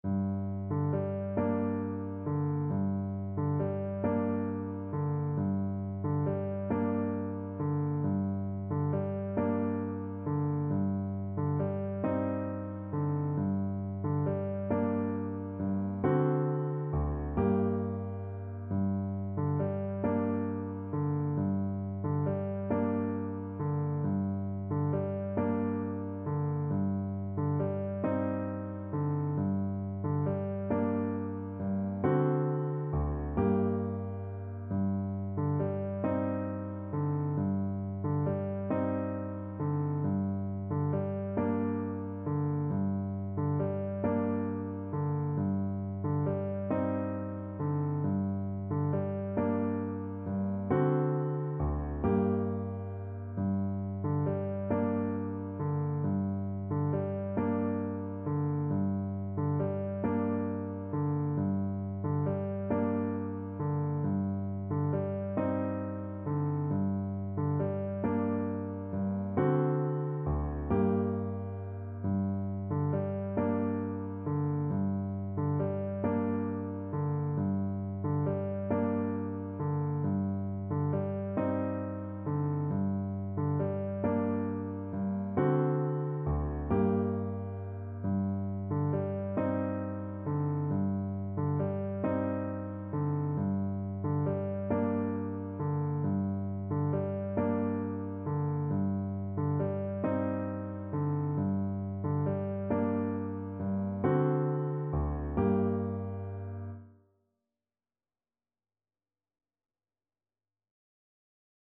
Play (or use space bar on your keyboard) Pause Music Playalong - Piano Accompaniment Playalong Band Accompaniment not yet available reset tempo print settings full screen
Gently rocking .=c.45
G minor (Sounding Pitch) A minor (Clarinet in Bb) (View more G minor Music for Clarinet )
6/8 (View more 6/8 Music)